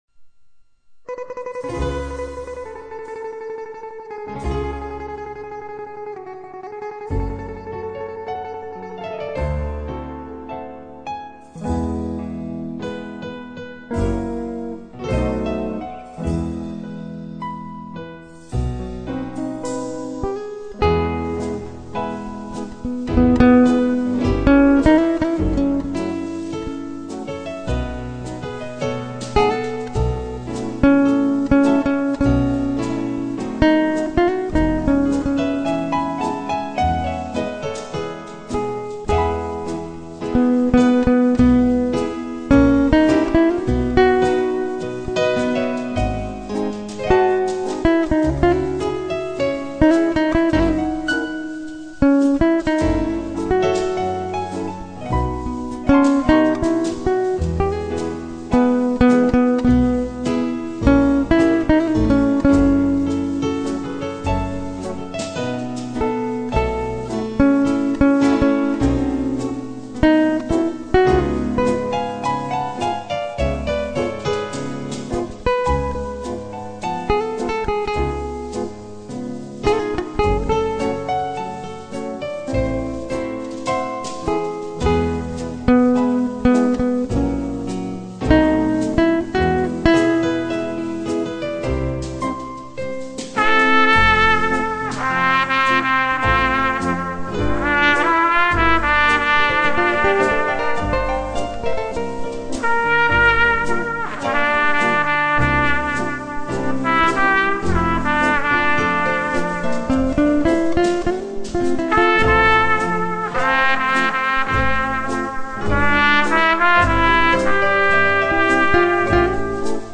in multiplay recording system